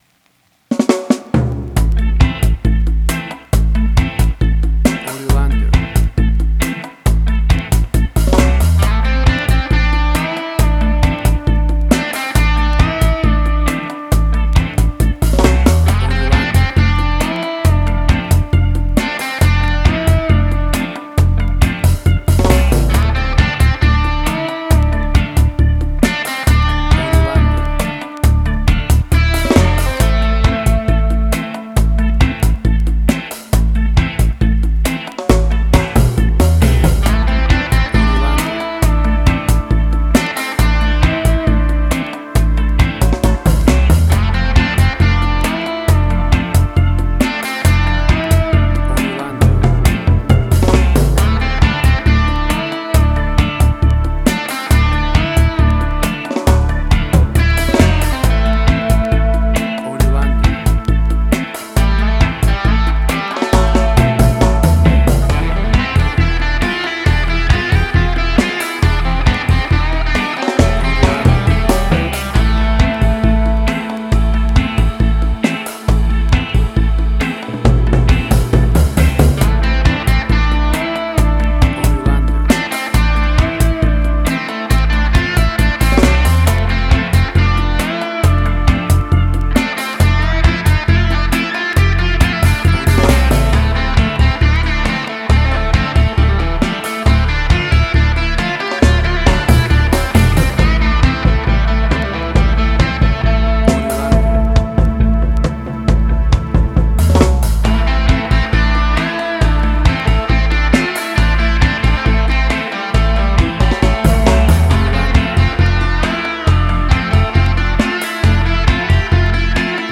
Reggae caribbean Dub Roots
Tempo (BPM): 68